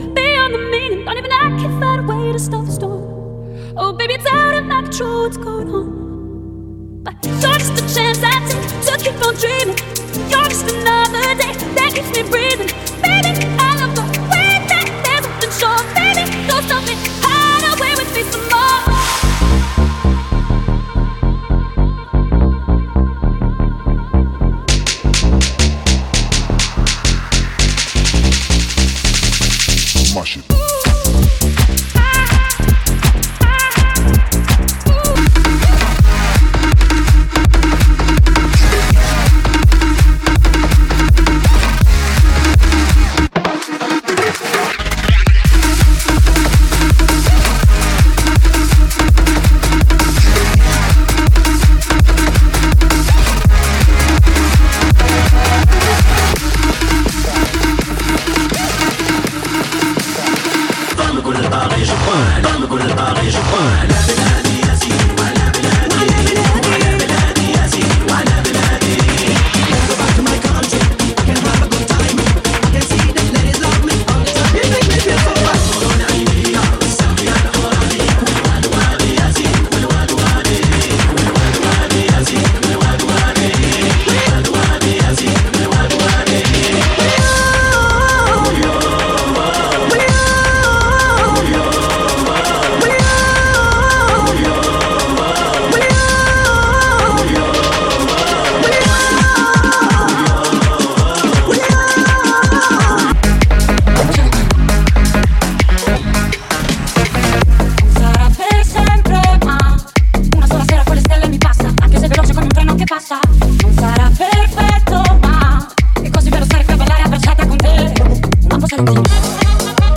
(32 count phrased) 160 BPM
dance, cardio, aerobics, Fitness…
60 minutes Tempo: 160 BPM Marque